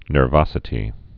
(nûr-vŏsĭ-tē)